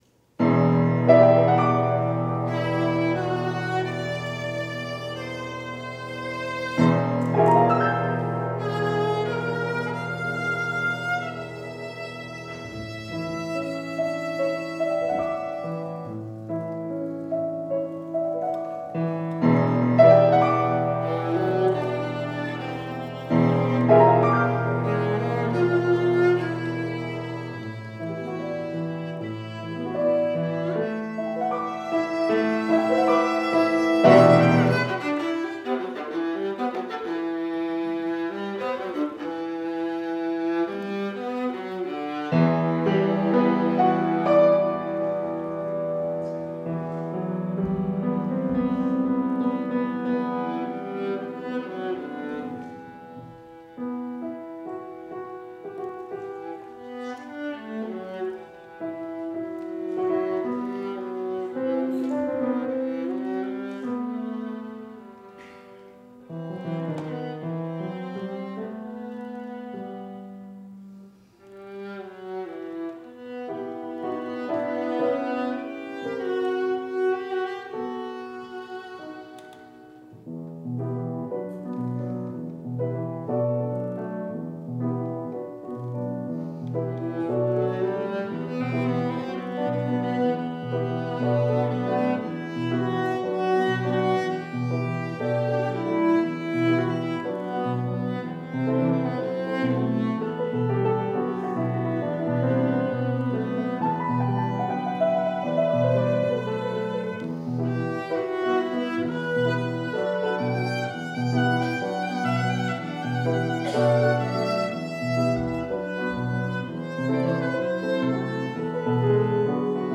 Recording (viola and piano version):